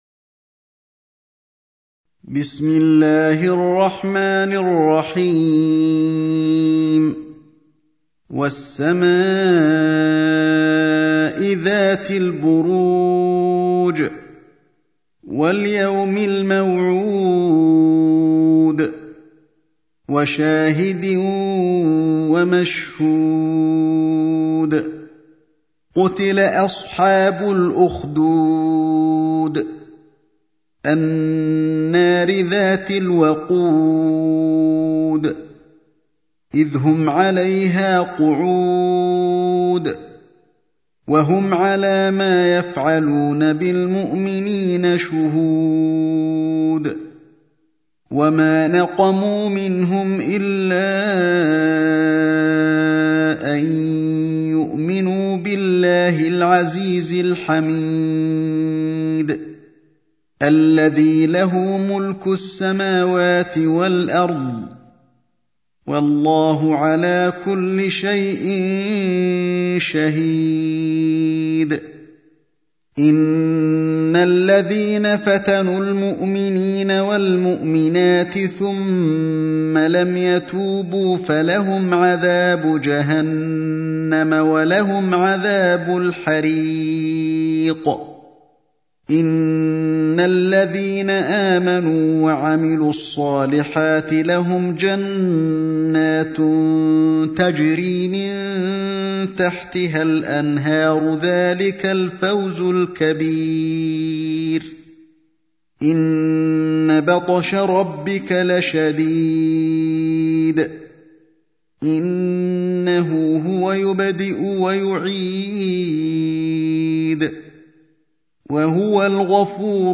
سورة البروج | القارئ علي الحذيفي